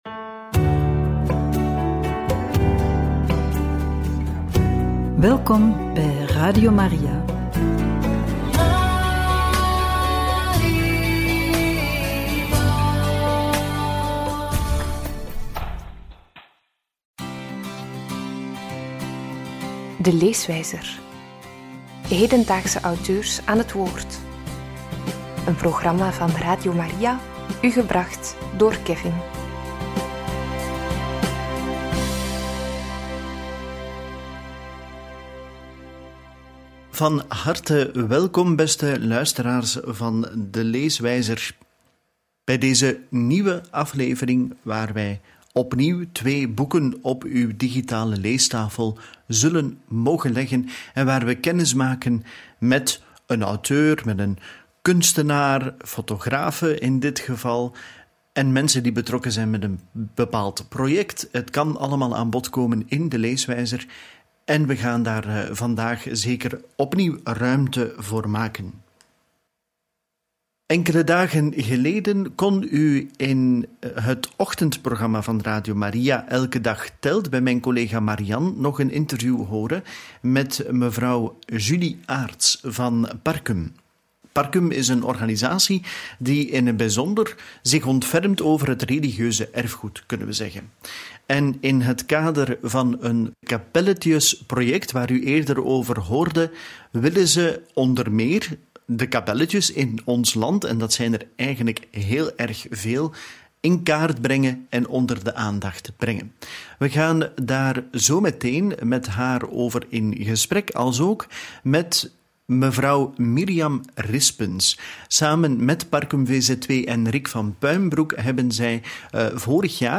Kapel gezocht, 15.000 verhalen in beweging, in gesprek met